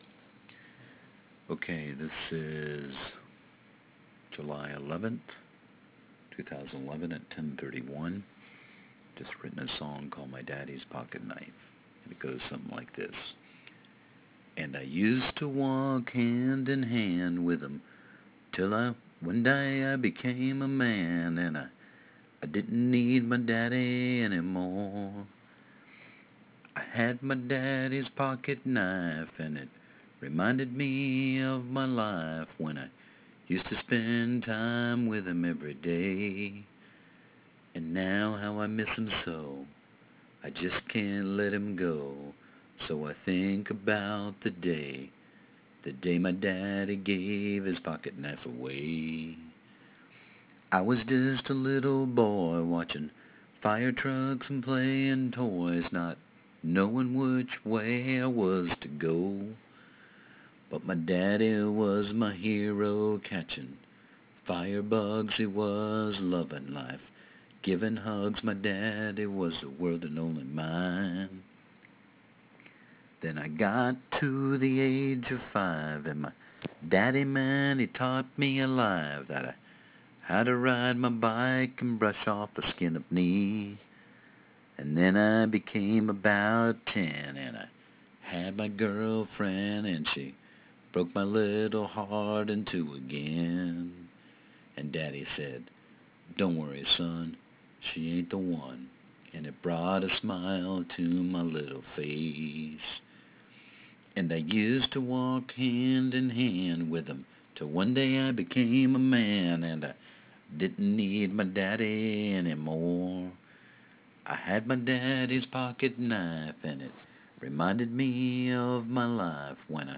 Singer(?)